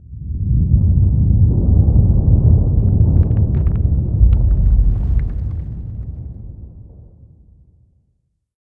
cave2.ogg